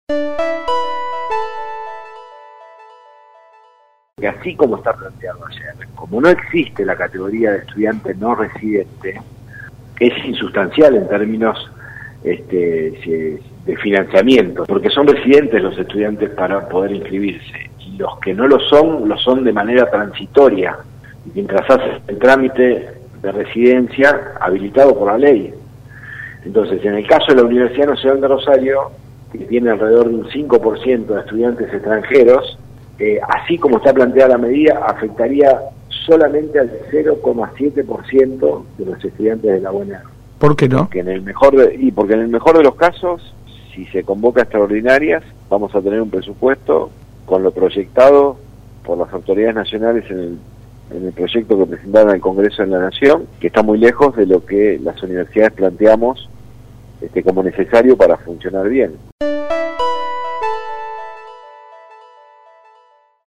En contacto con LT3 el Rector de la UNR Franco Bartolacci, afirmó que tal como se hizo el anuncio afecta al 0,7 por ciento de los estudiantes.